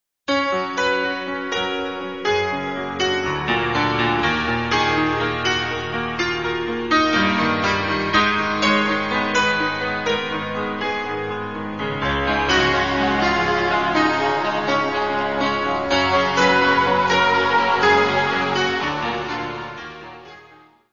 : stereo; 12 cm + folheto
Music Category/Genre:  Pop / Rock